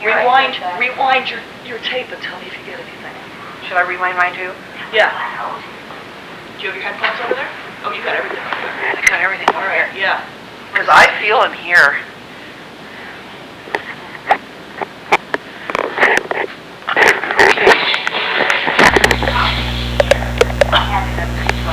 EVPs